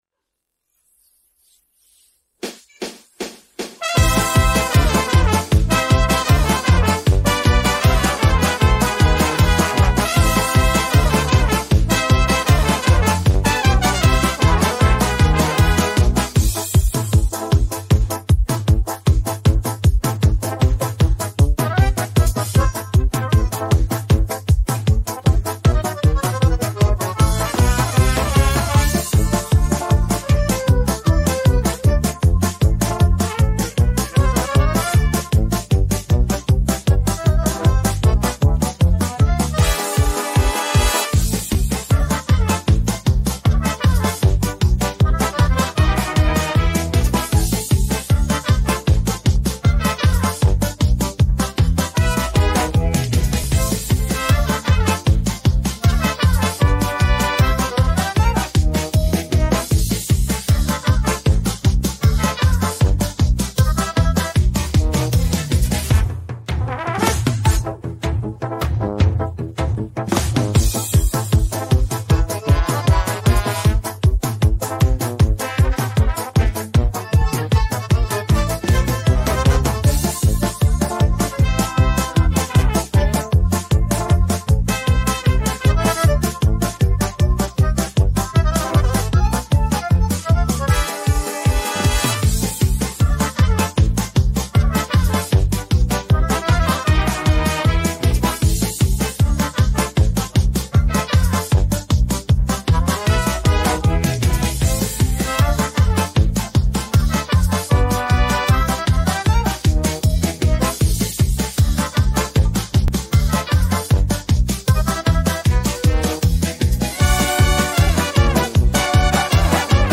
pop rock караоке 16